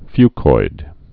(fykoid)